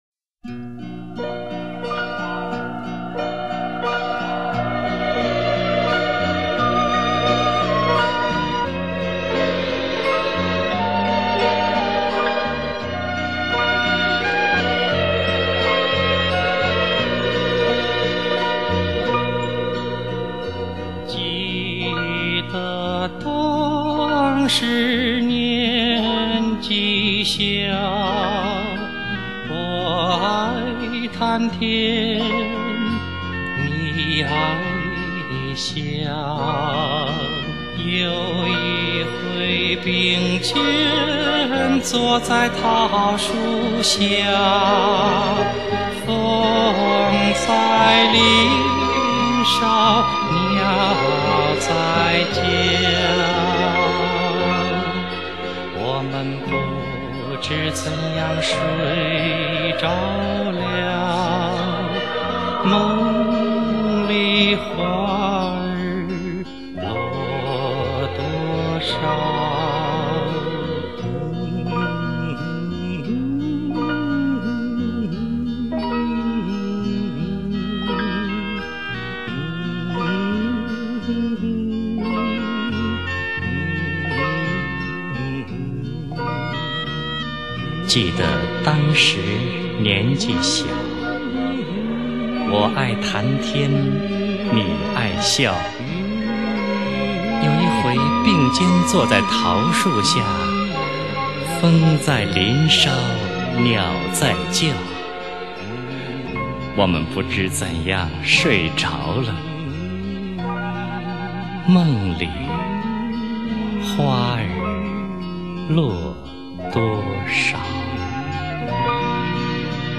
瘦瘦的聲音